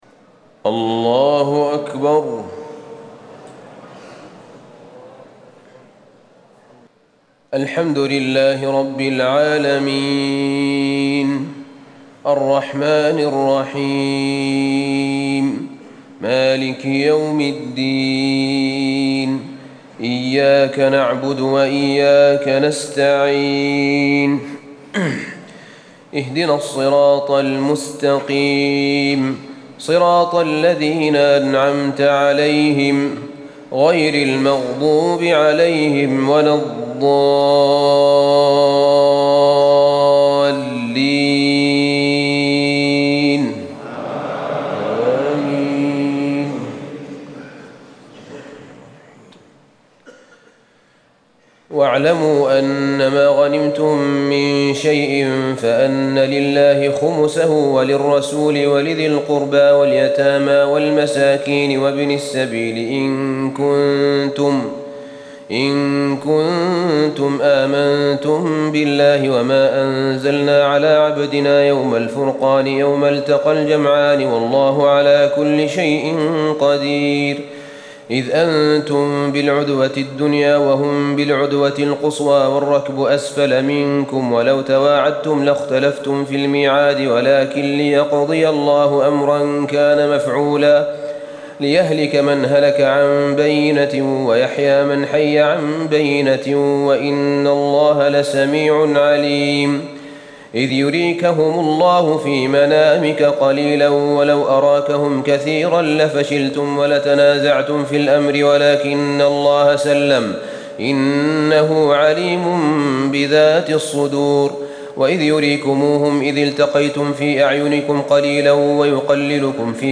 تهجد ليلة 30 رمضان 1437هـ من سورتي الأنفال (41-75) و التوبة (1-37) Tahajjud 30 st night Ramadan 1437H from Surah Al-Anfal and At-Tawba > تراويح الحرم النبوي عام 1437 🕌 > التراويح - تلاوات الحرمين